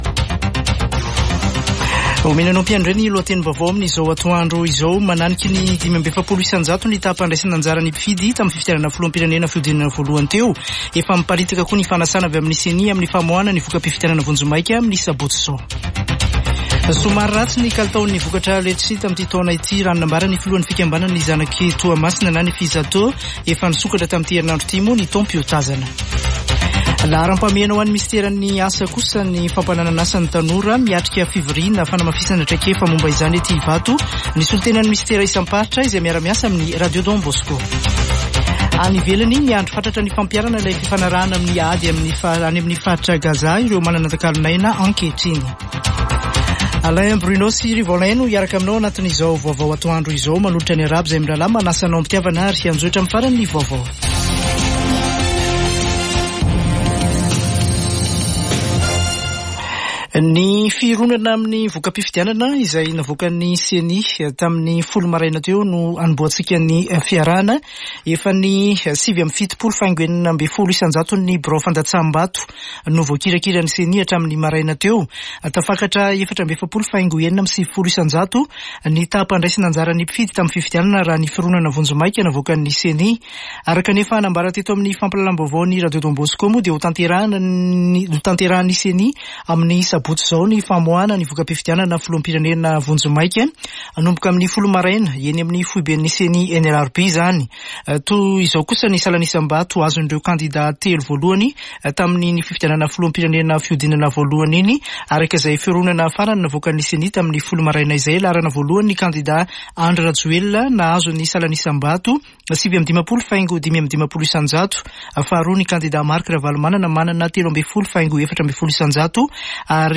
[Vaovao antoandro] Alakamisy 23 nôvambra 2023